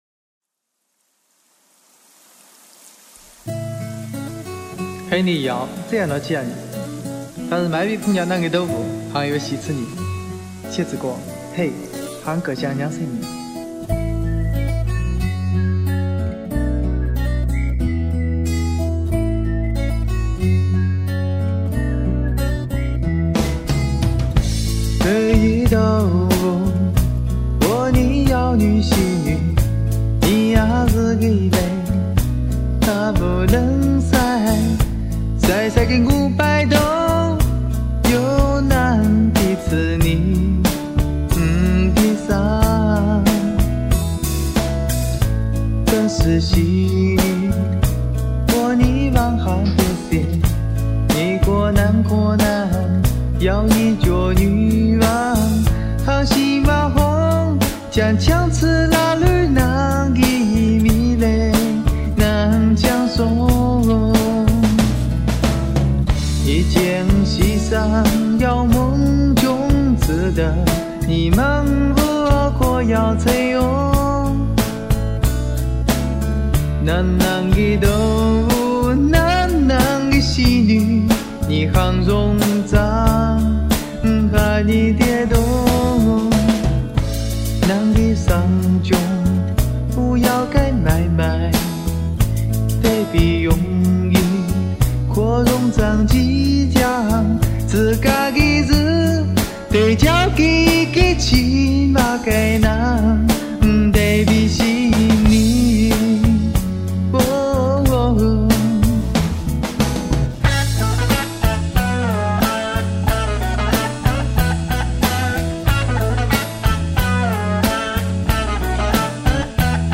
A modern Pop song in Wenzhou language
wenzhou modern song.mp3